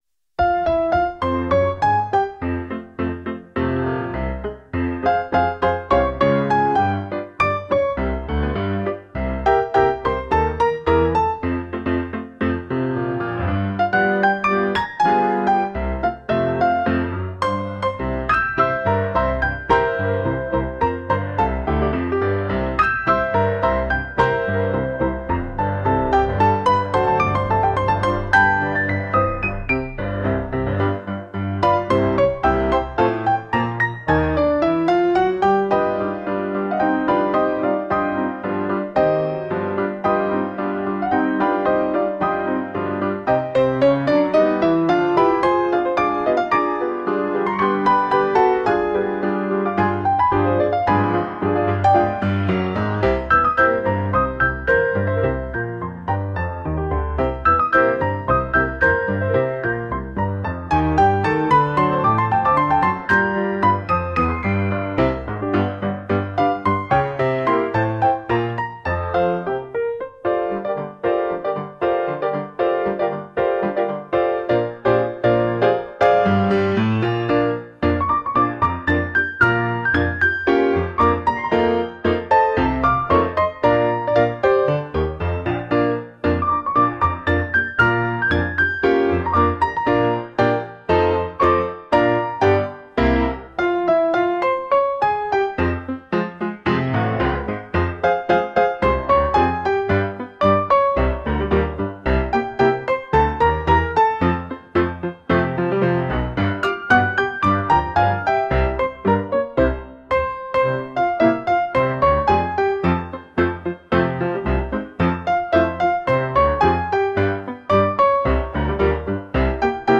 Инструментальная музыка